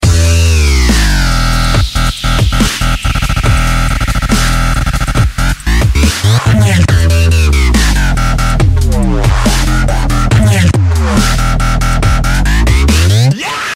• Качество: 128, Stereo
Bass
electro
отрывок из фестиваля